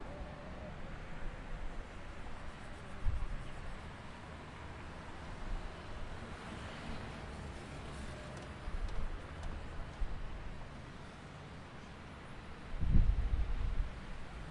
描述：波哥大直布罗陀公共街道（“Avenida 19”）的交通（哥伦比亚）以XY技术（44.1 KHz）排列的Zoom H4录制
Tag: 音景 环境 交通 现场录音 城市